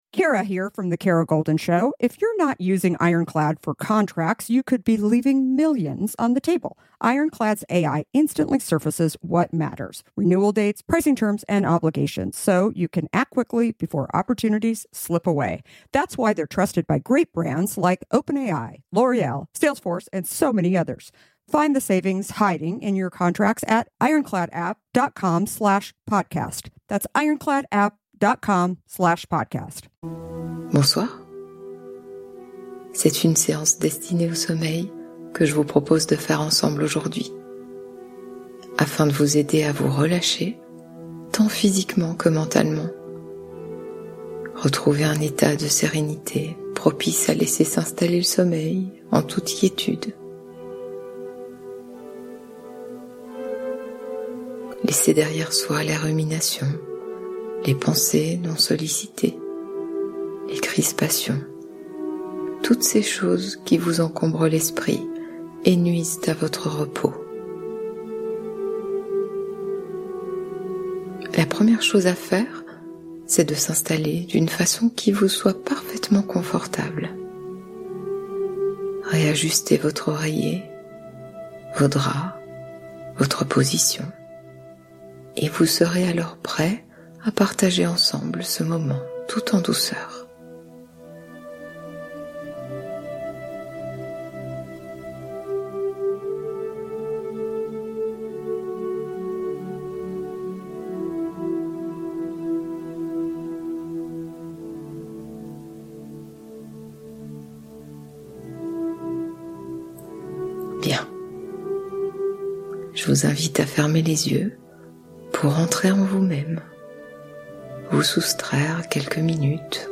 Murmures de l'Océan : Hypnose somnifère rythmée par les vagues